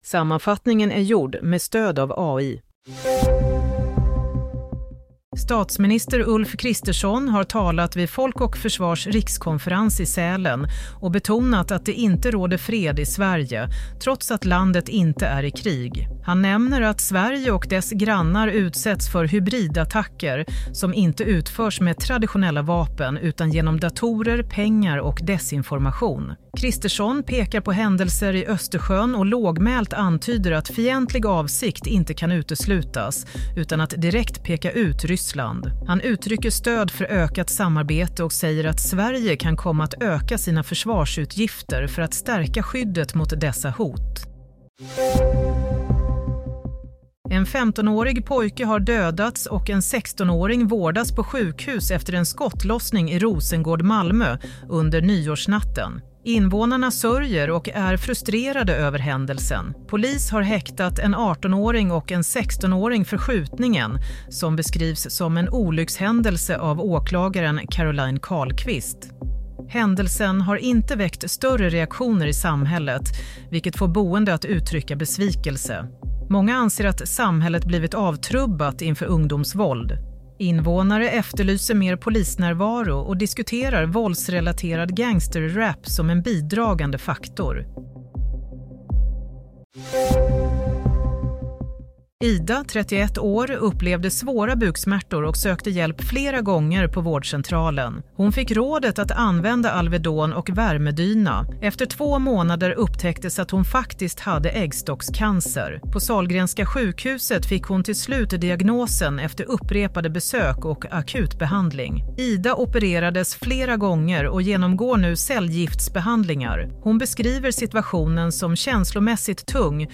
Play - Nyhetssammanfattning – 12 januari 16:00